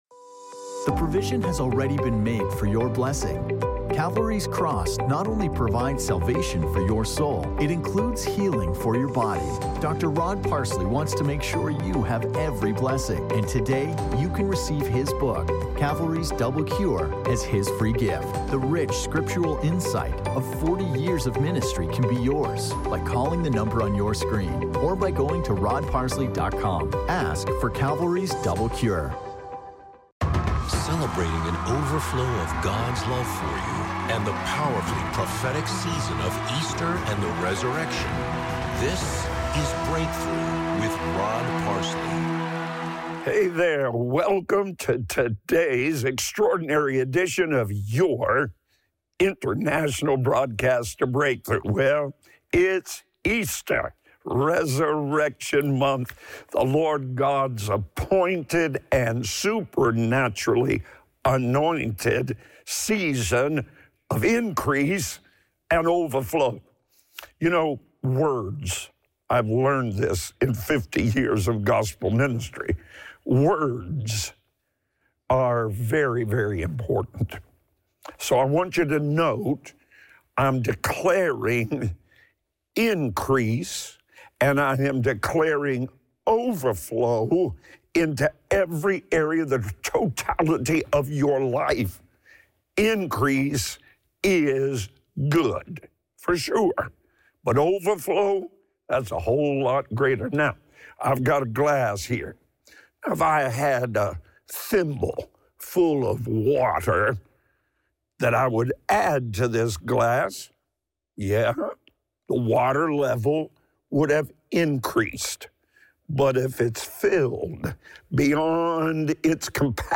Audio only from the daily television program Breakthrough